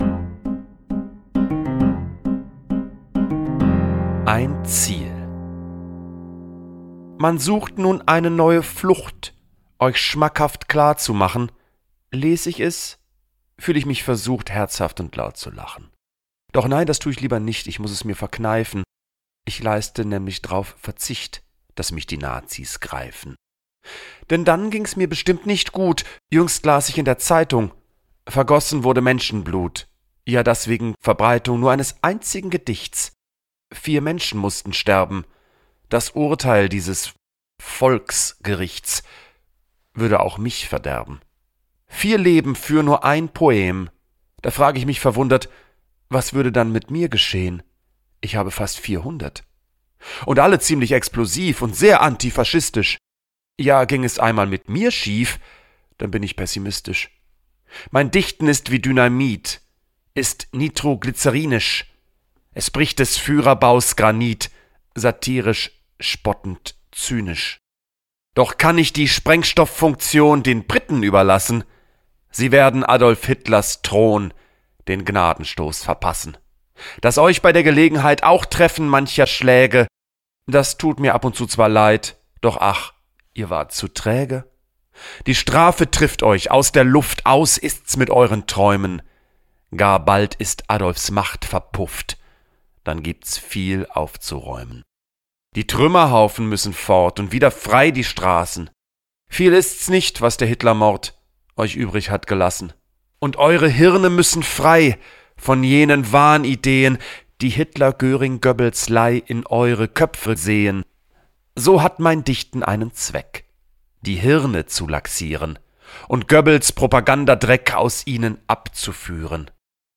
Musik: Kristen & Schmidt, Wiesbaden
Jan-Boehmemann_ZIEL_mit-Musik_raw.m4a